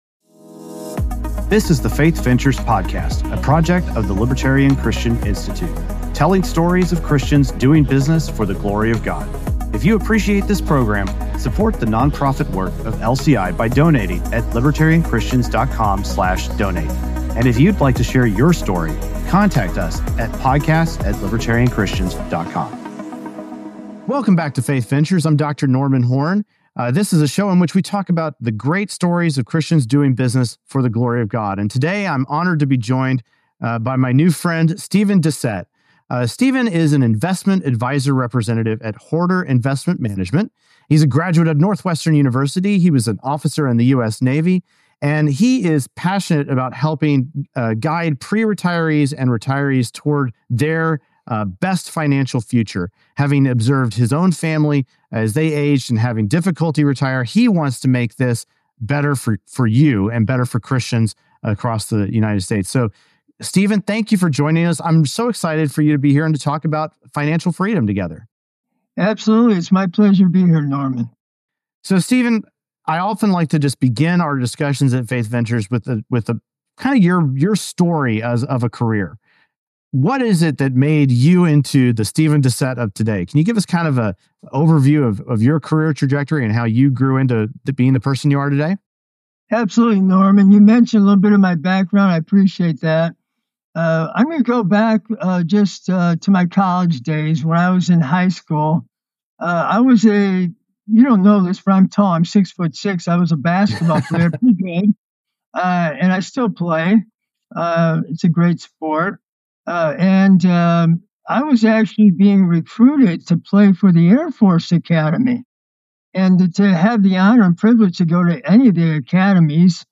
Faith Ventures Podcast